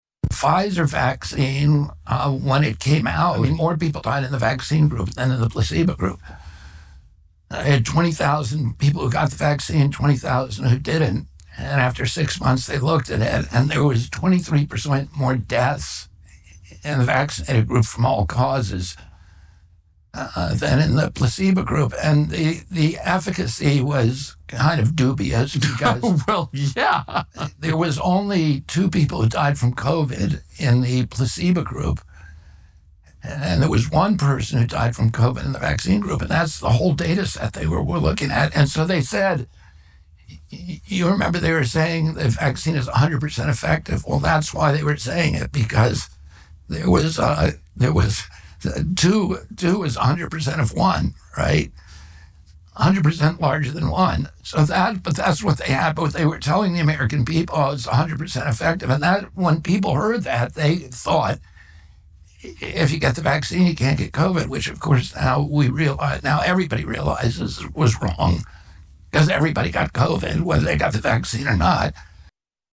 RFK Jr. Interview:
RFK Jr. did an interview with Tucker Carlson earlier this week where the two discussed vaccines.